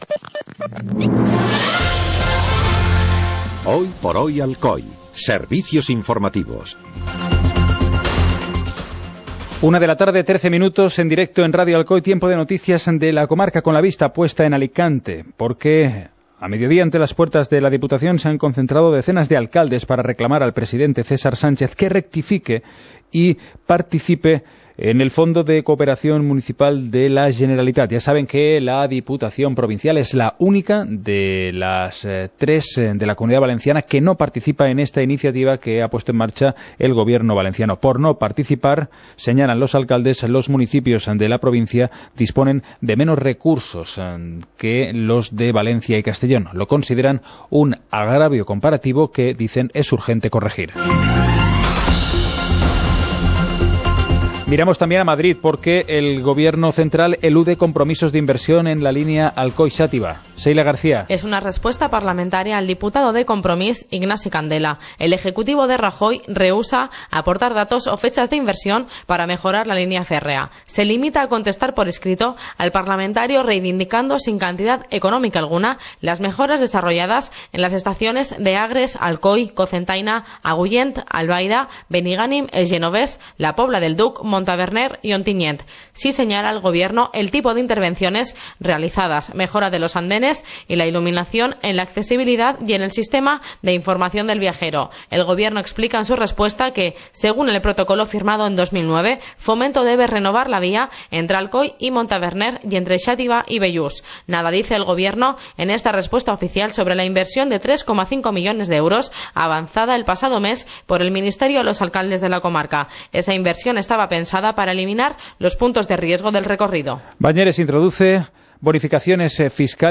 Informativo comarcal - miércoles, 15 de marzo de 2017